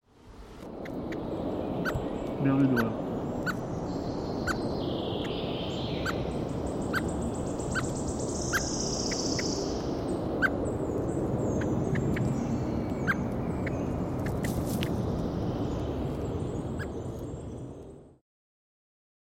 merle-noir.mp3